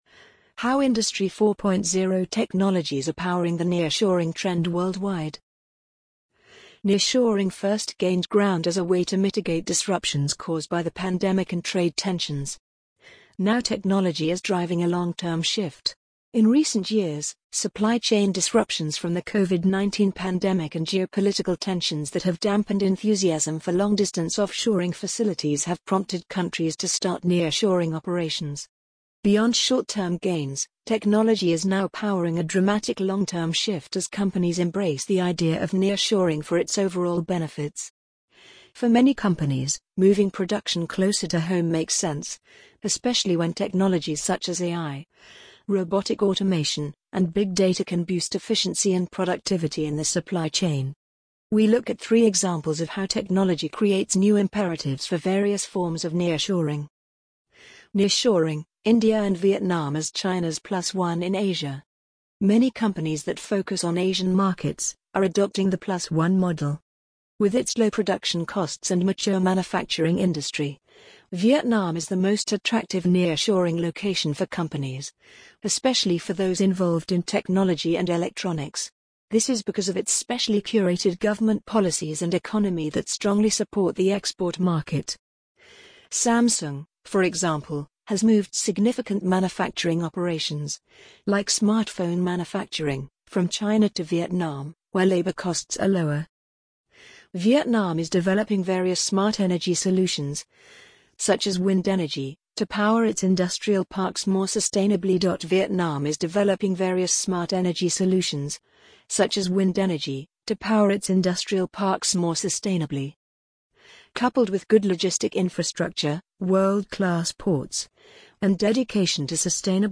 amazon_polly_47955.mp3